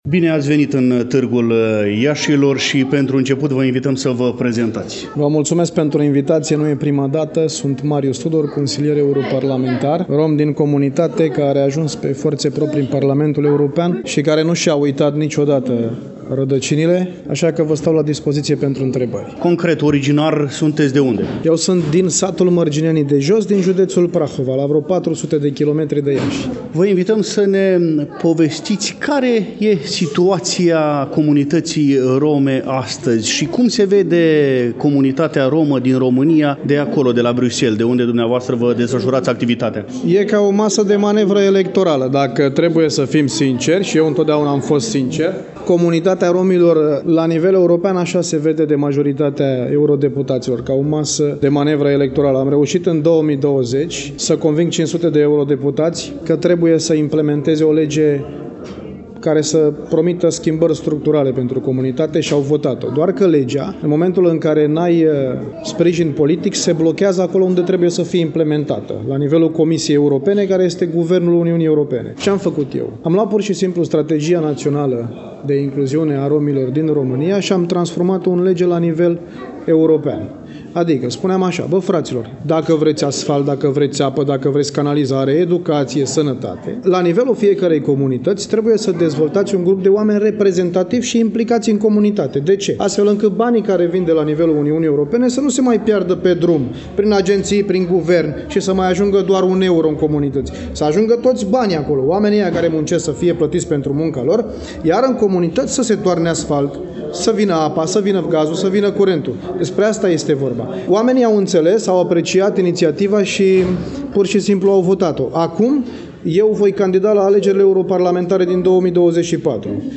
La evenimentul care constituie subiectul ediției de astăzi a emisiunii noastre, am stat de vorbă